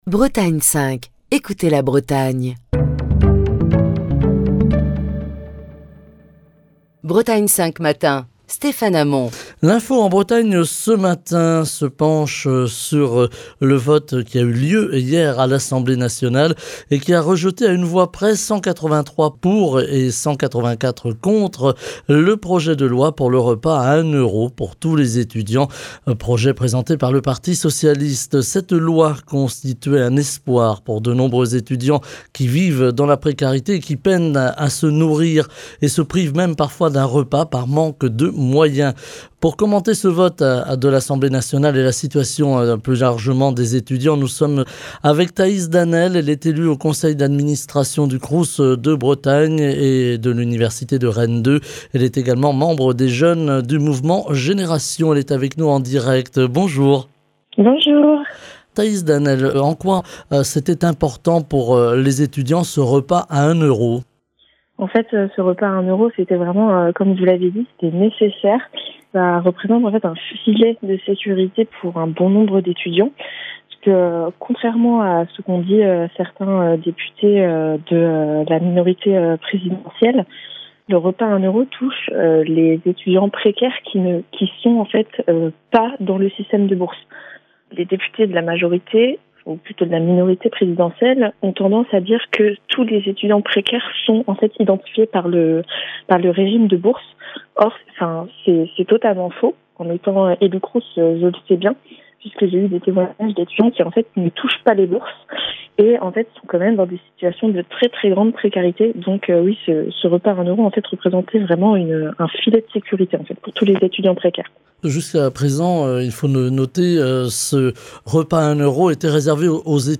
Émission du 10 février 2023. Hier, l'Assemblée nationale, a rejeté à une voix près (183 « pour », 184 « contre ») le projet de loi pour le repas à un euro pour tous les étudiants présenté par le parti socialiste. Cette loi constituait un espoir pour de nombreux étudiants qui vivent dans la précarité et qui peinent à se nourrir, et se privent parfois d'un repas par manque de moyens.